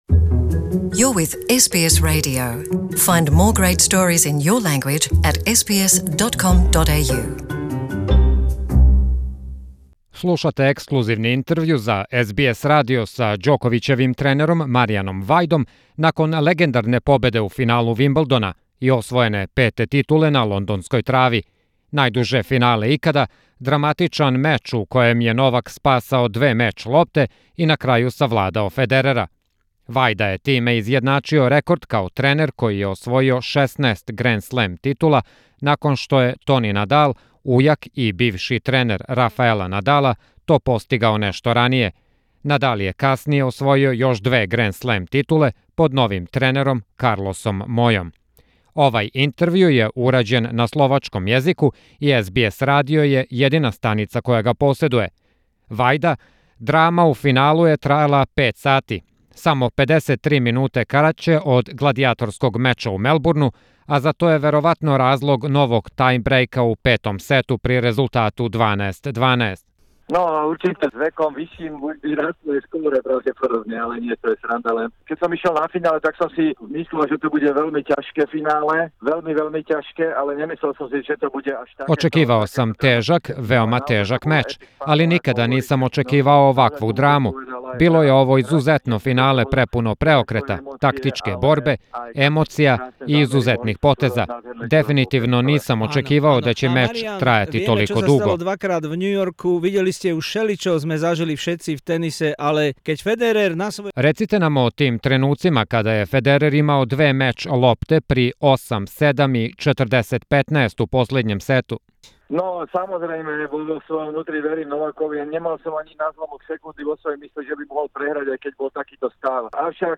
Мариан Вајда говори ексклузивно за СБС радио, и то је једини интерју који је икоме дао пошто је Новак Ђоковић освојио 16-ту Гранд Слем титулу у Вимблдону победивши Рооџера Федерера.